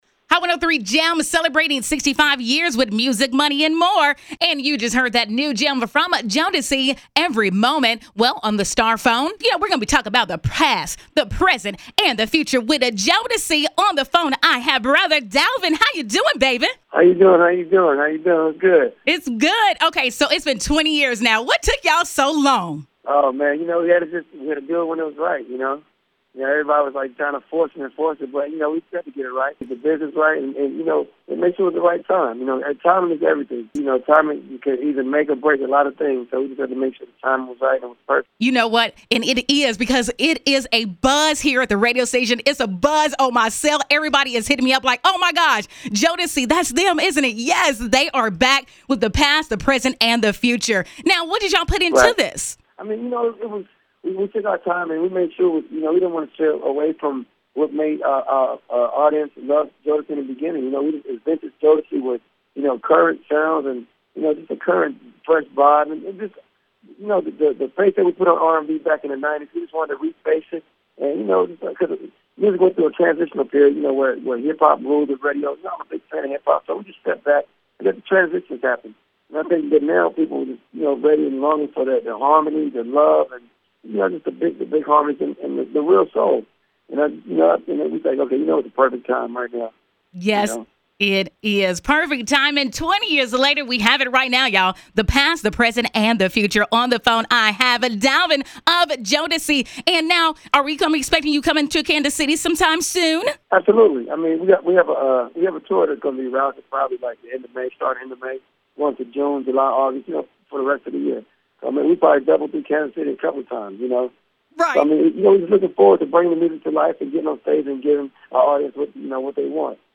jodeci-interview-2-rk.mp3